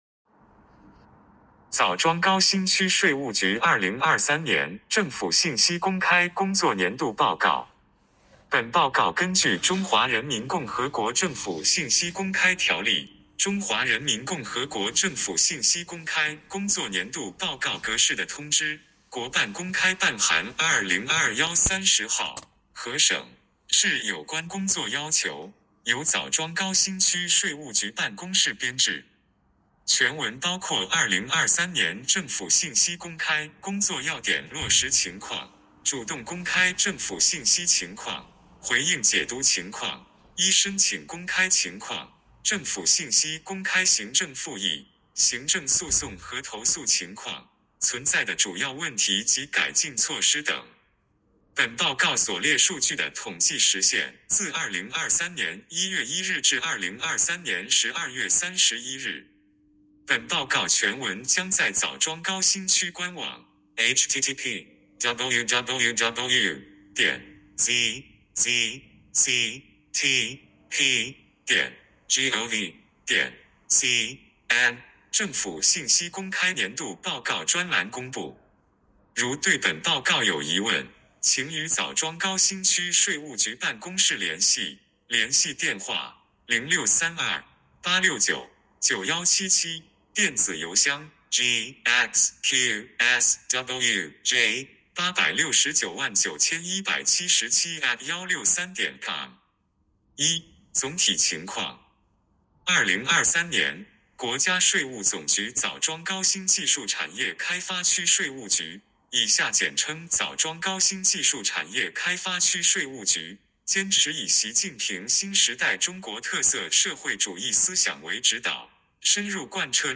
点击接收年报语音朗读 枣庄高新区税务局2023年政府信息公开工作年度报告 作者： 来自： 时间：2024-01-26 本报告根据《中华人民共和国政府信息公开条例》《中华人民共和国政府信息公开工作年度报告格式》的通知（国办公开办函〔2021〕30号）和省、市有关工作要求，由枣庄高新区税务局办公室编制。